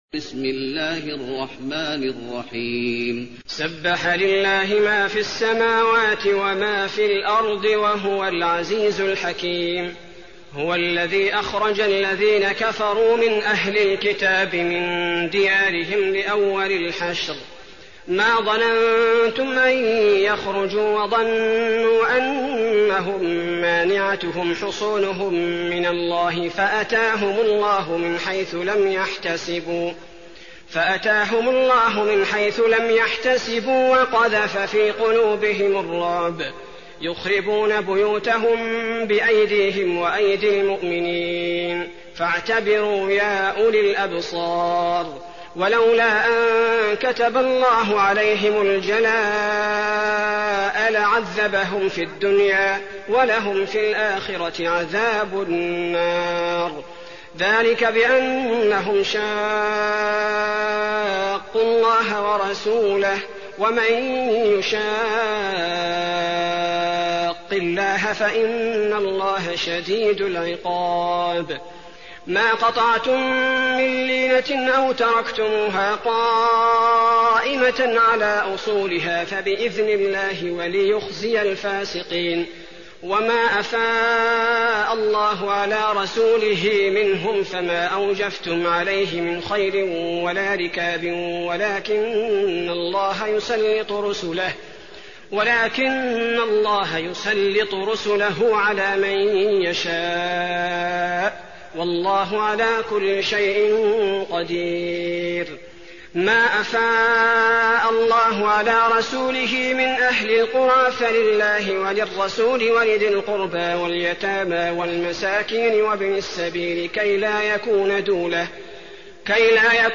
المكان: المسجد النبوي الحشر